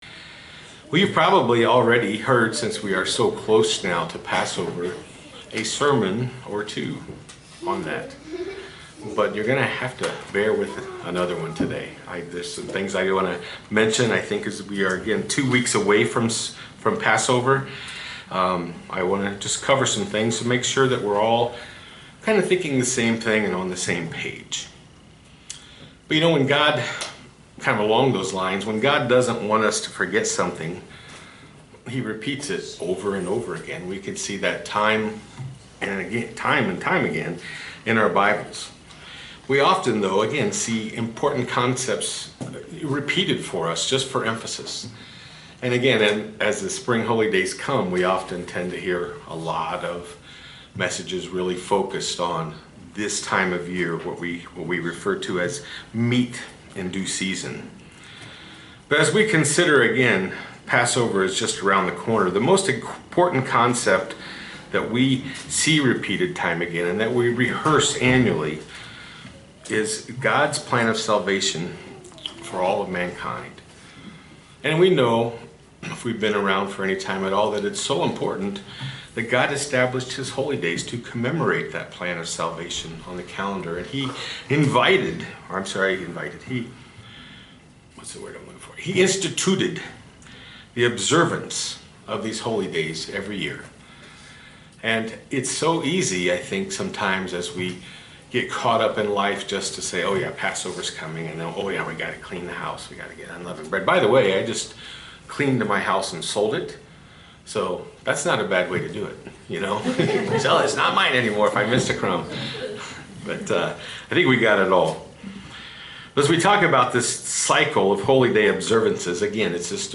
Sermons
Given in Roanoke, VA